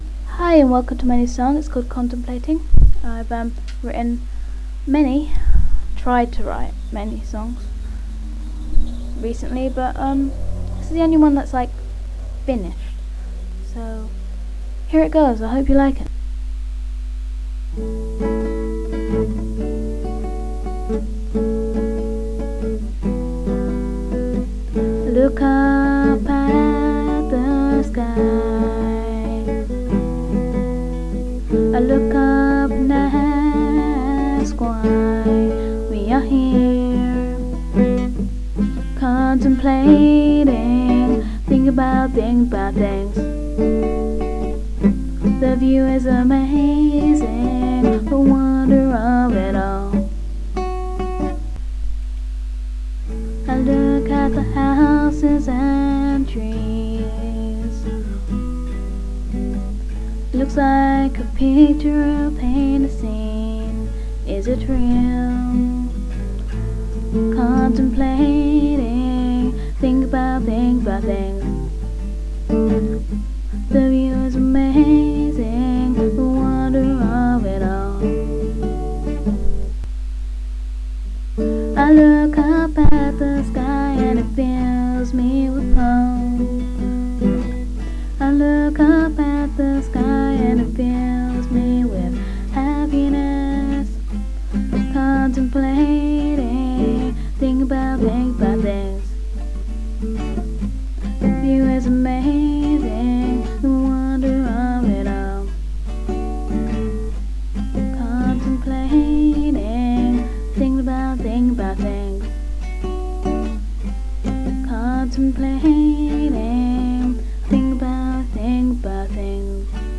I can use the mic. better now!! It's bitty, argh, that is sooooooo annoyin!! a new verion without the bittyness will be up when I can be bothered.
Intro: A D A Em